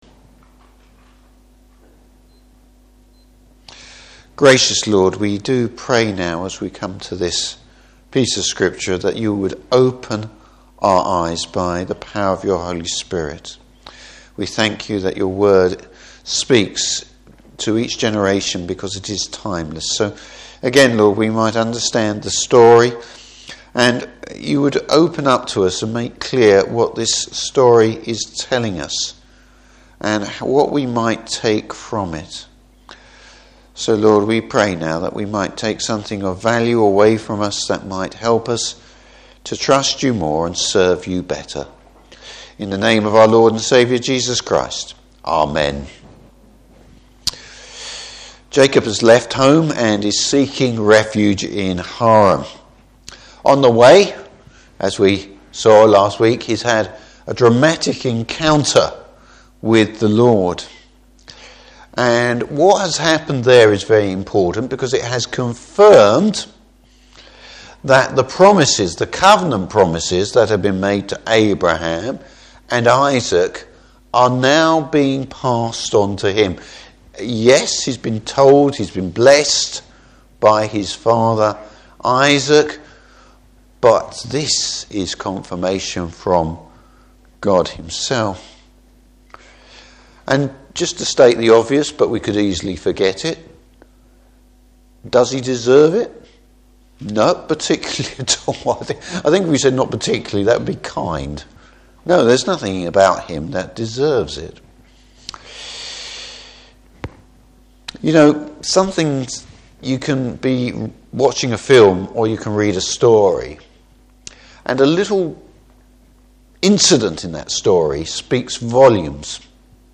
Service Type: Evening Service Jacob’s finds safety.